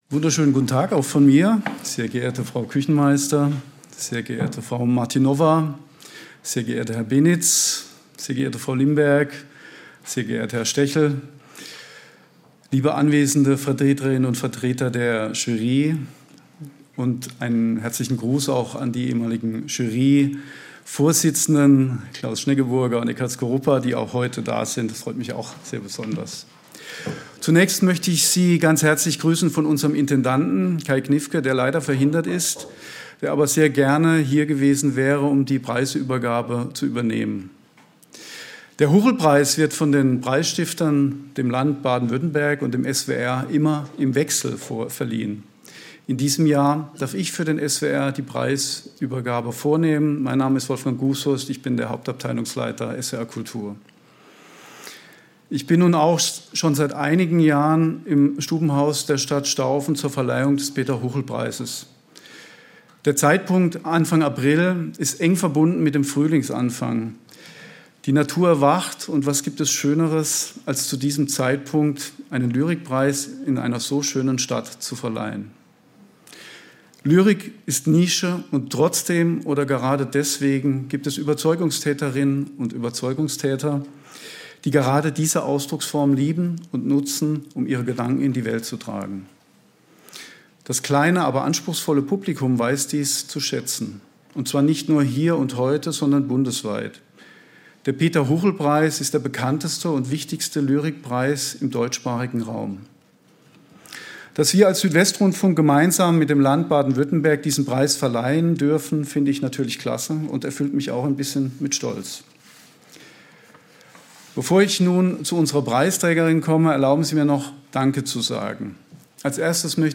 Preisübergabe